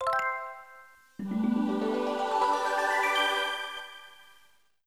Sega NAOMI Startup.wav